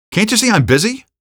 vo / npc / male01